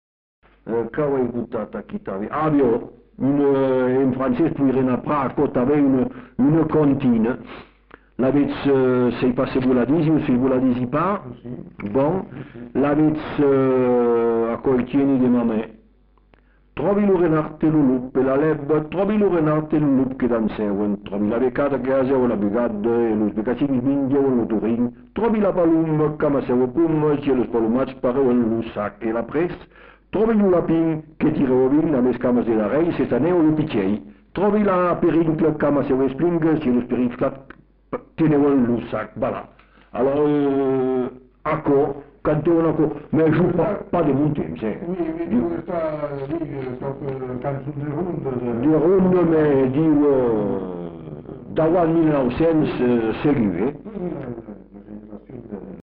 Aire culturelle : Bazadais
Lieu : Bazas
Genre : chant
Effectif : 1
Type de voix : voix d'homme
Production du son : chanté
Classification : enfantines diverses